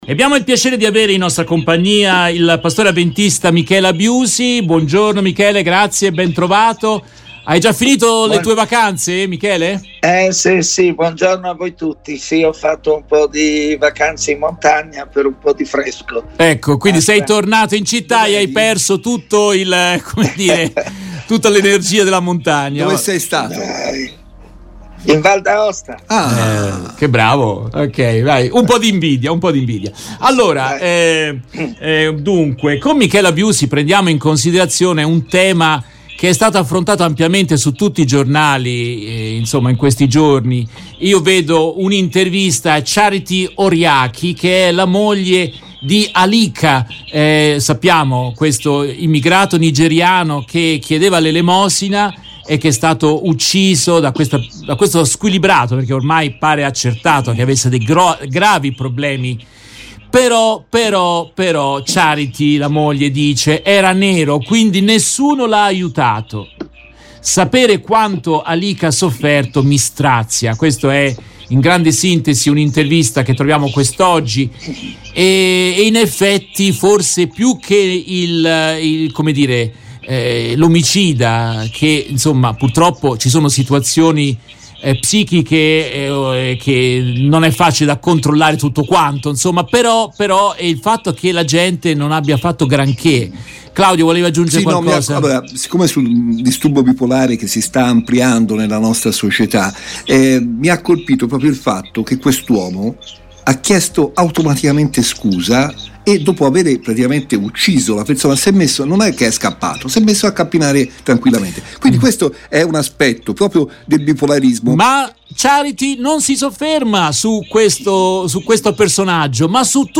In questa intervista tratta dalla diretta RVS del 03 agosto 2021